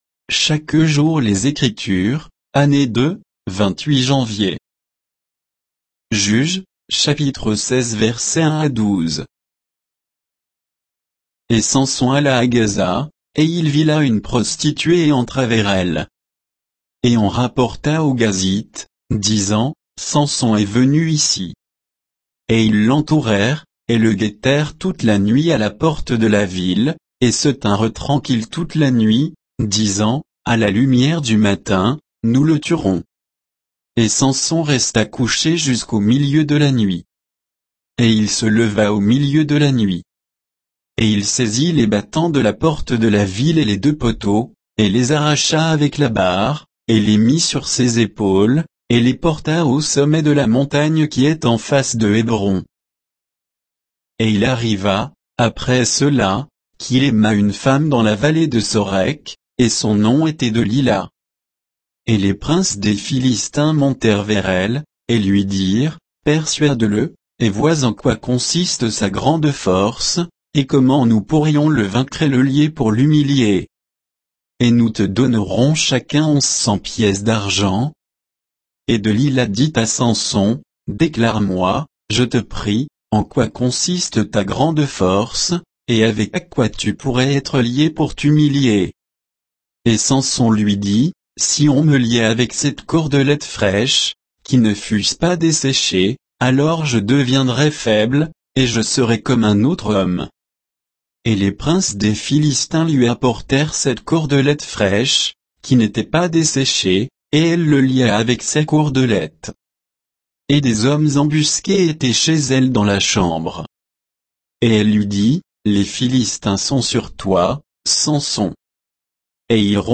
Méditation quoditienne de Chaque jour les Écritures sur Juges 16